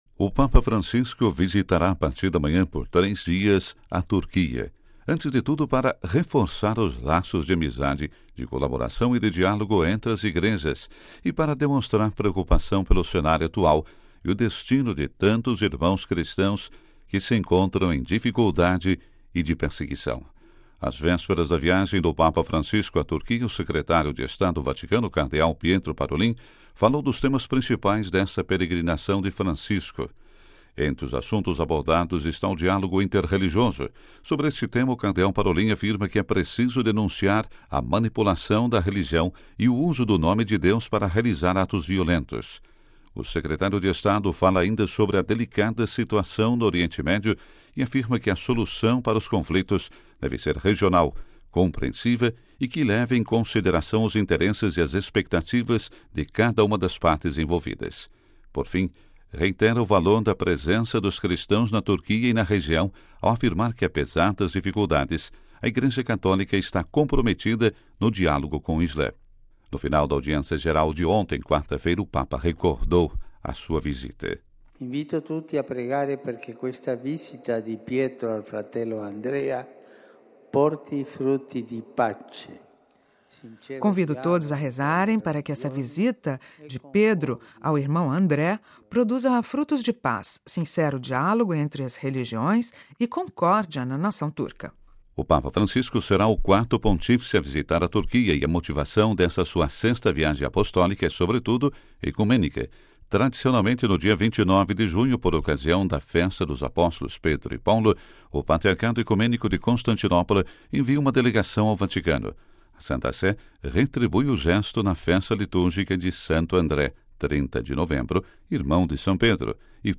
Boletim da Rádio Vaticano - 27/11